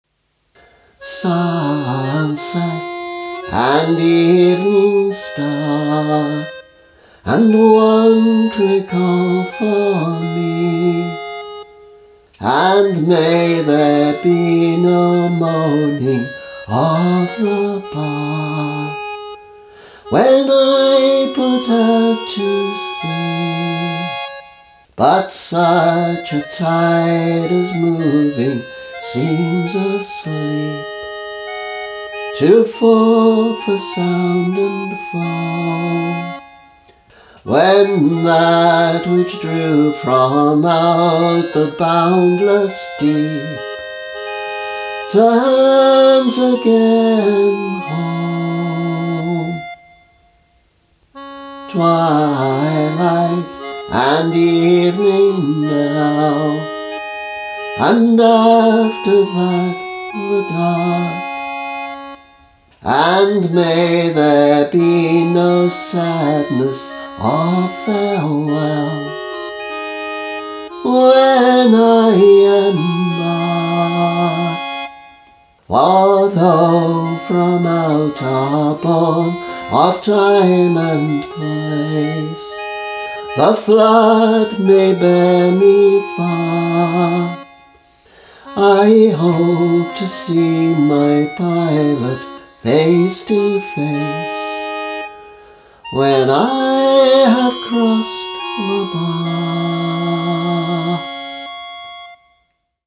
I’ve posted a guitar-accompanied version of Tennyson’s poem before, but it suddenly occurred to me in the middle of the night that it might sound nice with a free-reed accompaniment. Since I don’t play concertina etc., I had to fake it with a Yamaha keyboard.
emastered_crossing-the-bar-reed.mp3